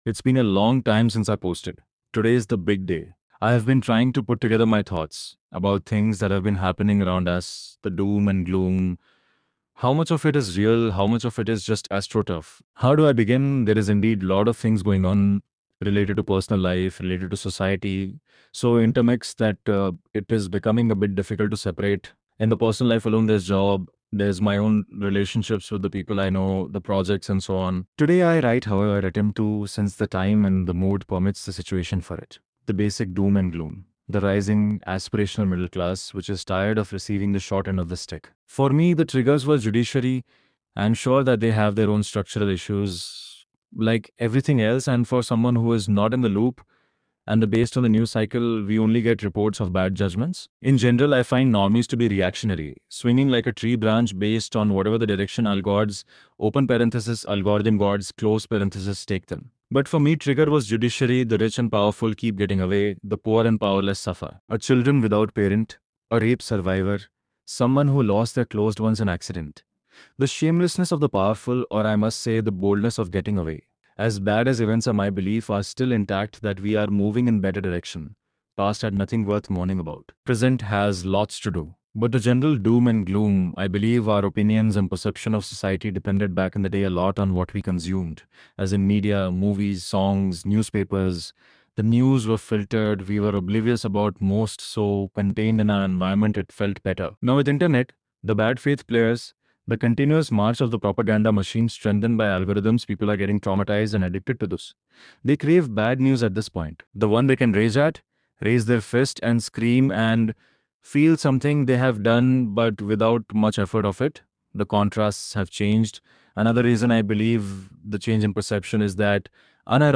It's a book, i used the vision to grab the hindi text and then generated the audio. I like this old school radio type narrative.